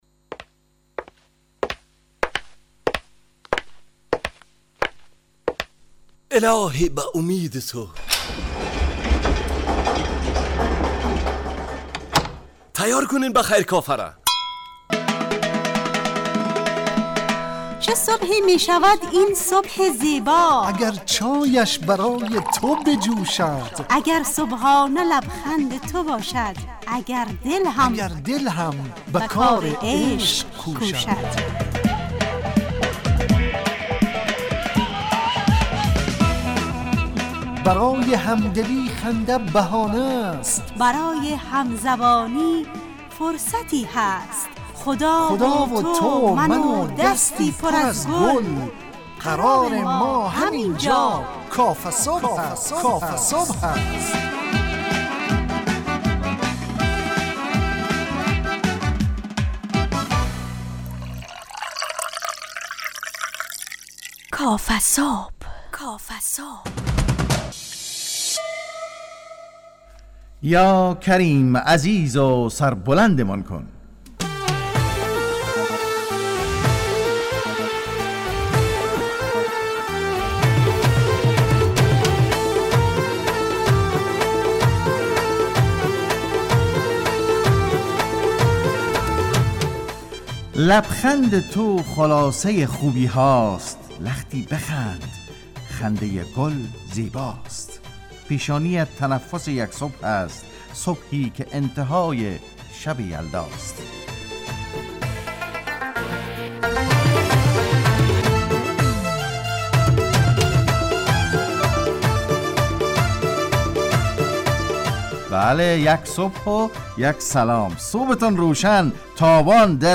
کافه صبح - مجله ی صبحگاهی رادیو دری با هدف ایجاد فضای شاد و پرنشاط صبحگاهی همراه با طرح موضوعات اجتماعی، فرهنگی، اقتصادی جامعه افغانستان همراه با بخش های کارشناسی، نگاهی به سایت ها، گزارش، هواشناسی و صبح جامعه